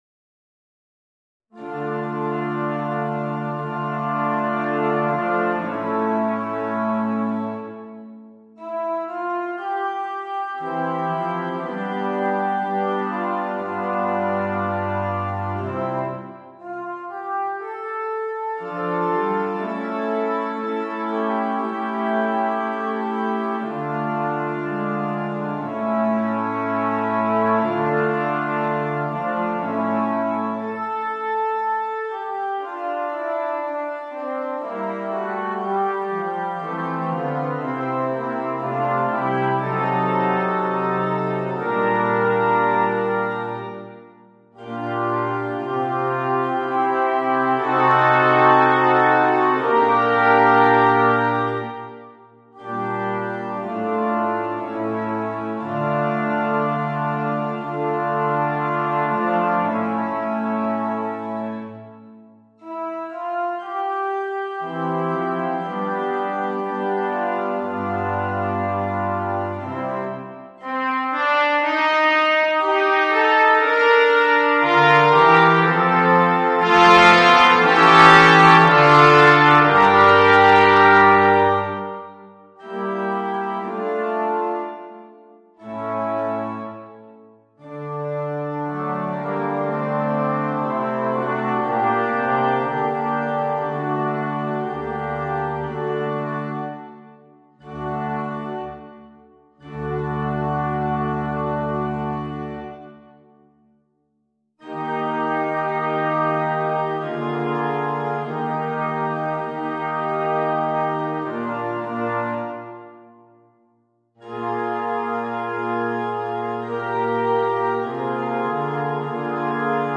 Voicing: 2 Trumpets, 2 Trombones